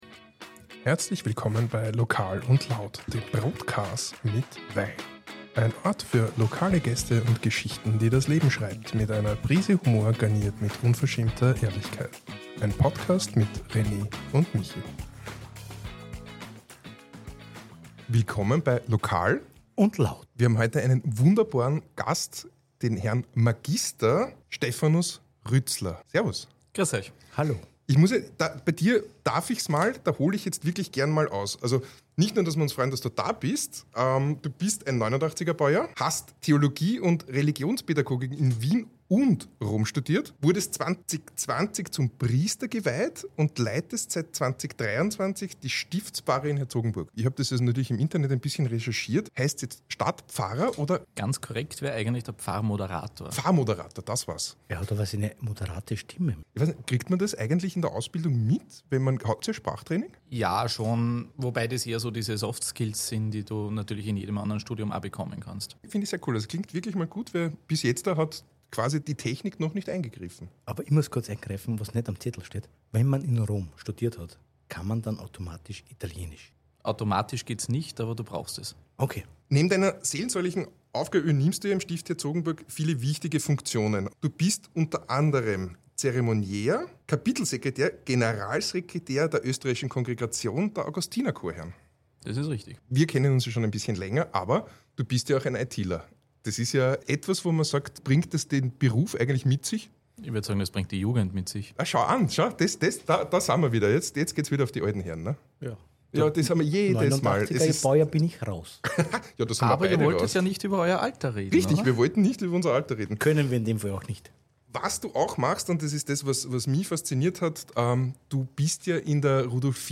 Eine entspannte, offene Unterhaltung über Glauben, Alltag im Stift und die Frage, wie Kirche heute eigentlich funktionieren kann.